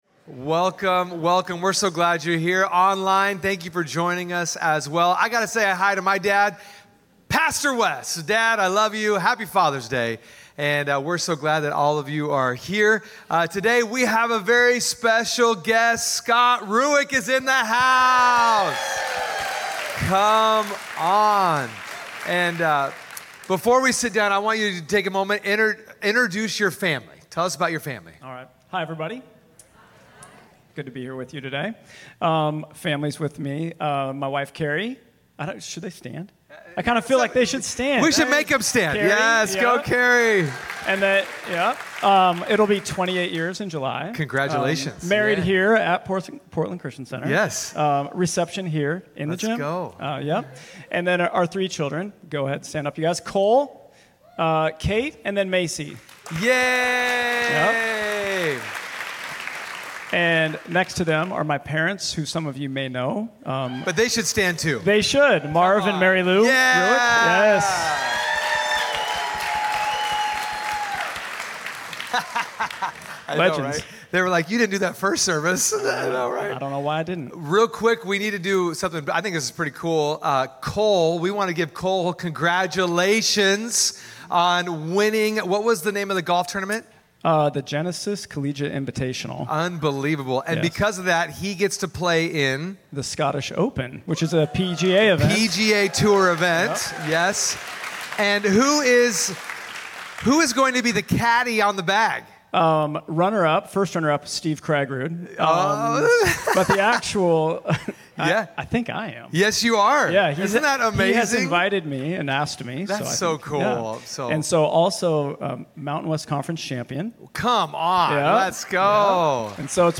Father's Day Message